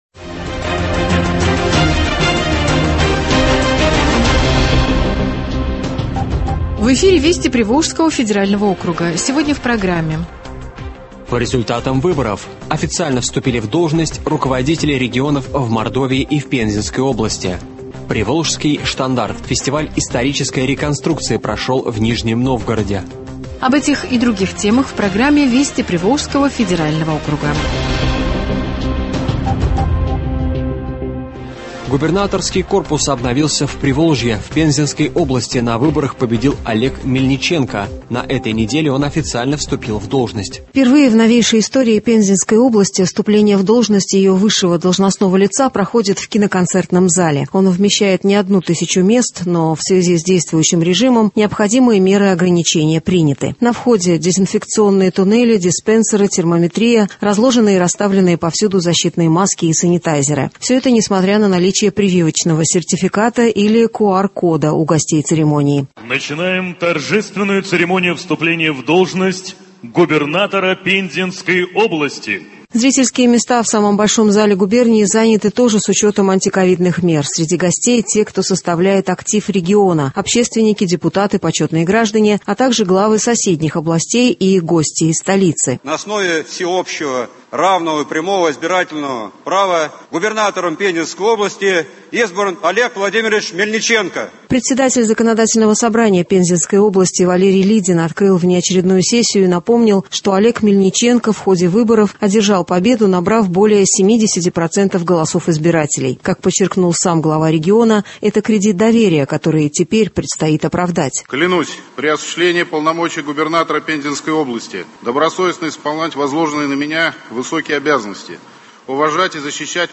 Радиообзор событий в регионах ПФО.